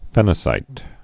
(fĕnə-sīt) or phen·a·kite (-kīt)